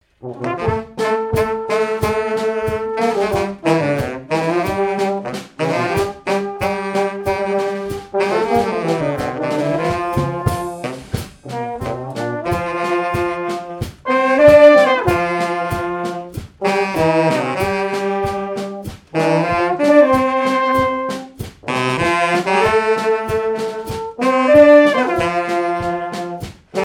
circonstance : fiançaille, noce
Répertoire pour un bal et marches nuptiales
Pièce musicale inédite